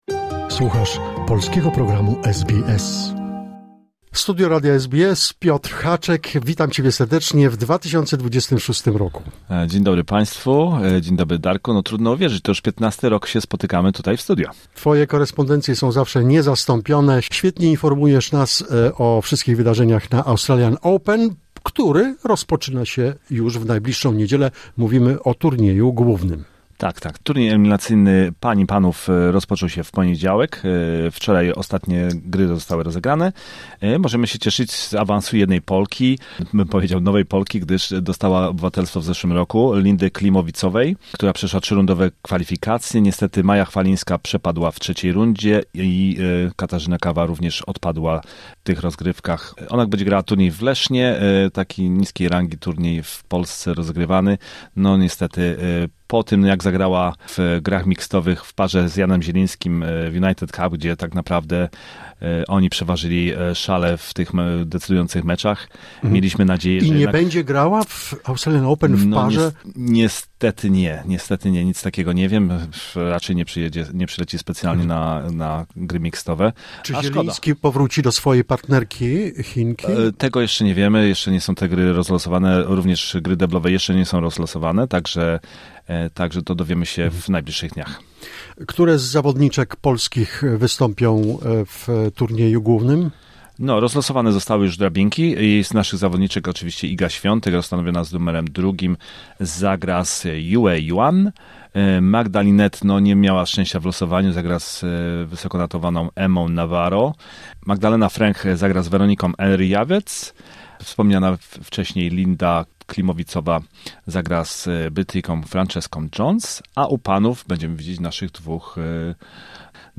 in studio SBS, Melbourne.